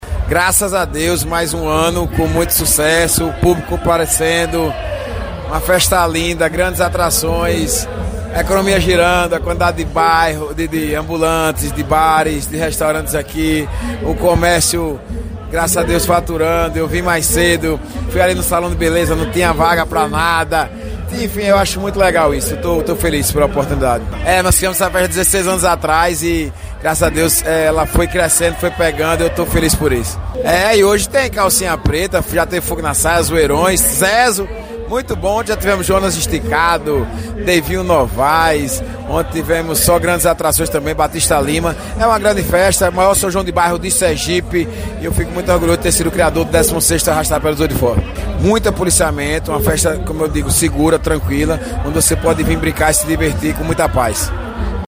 FÁBIO MITIDIERI, GOVERNADOR DE SERGIPE